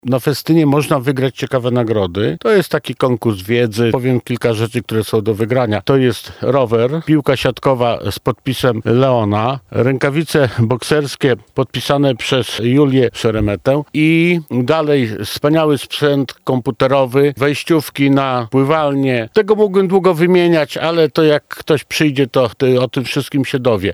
– mówi Zbigniew Jurkowski, Radny Rady Miasta Lublin.